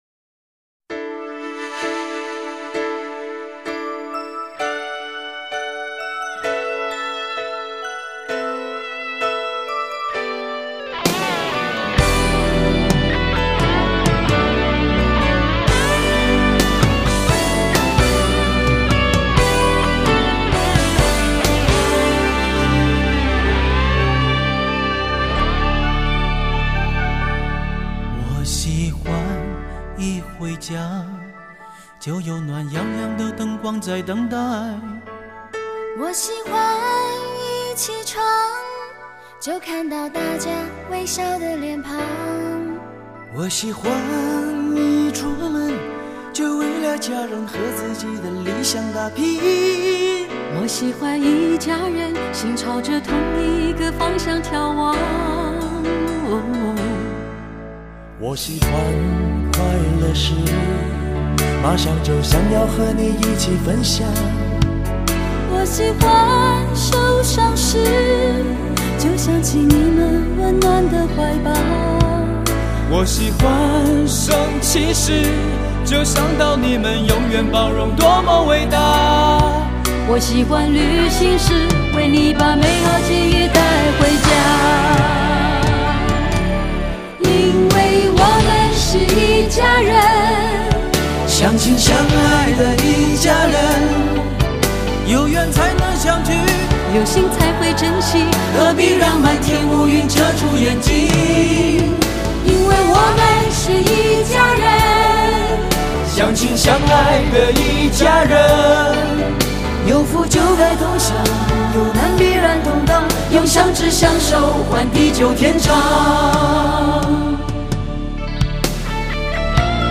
华语单曲